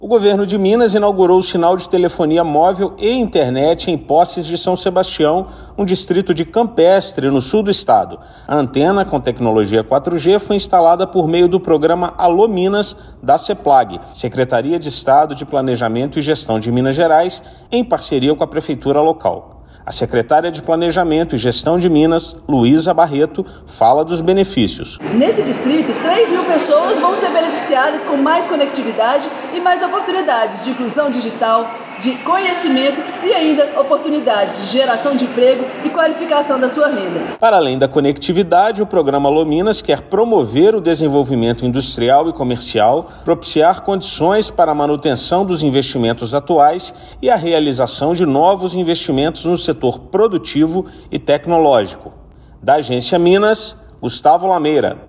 O Governo de Minas, inaugurou o sinal de telefonia móvel com tecnologia 4G no distrito de Posses de São Sebastião, no município de Campestre, no Sul de Minas. A antena foi implantada por meio do programa Alô, Minas!, da Secretaria de Estado de Planejamento e Gestão (Seplag-MG), em parceria com a Prefeitura de Campestre. Ouça matéria de rádio.